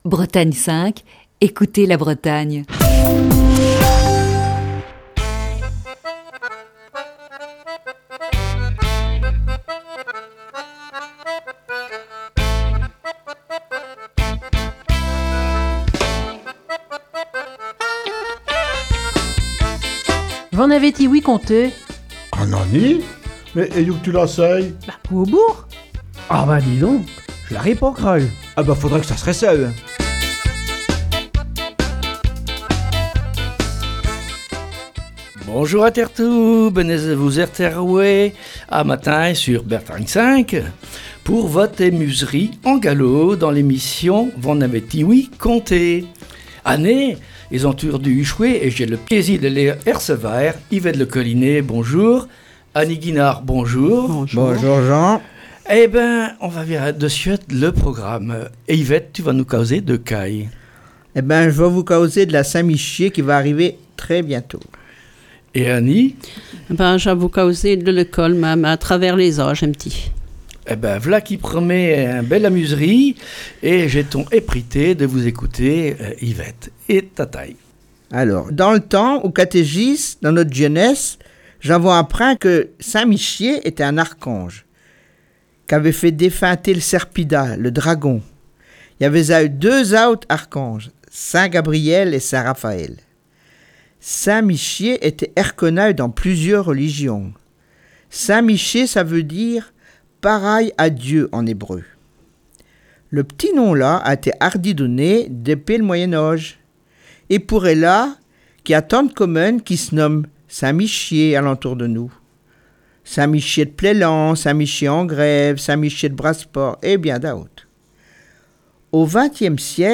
Émission du 4 octobre 2020. La troupe de V'en avez ti-ouï conté ? est réunie ce matin au micro de Bretagne 5 pour évoquer la Saint-Michel.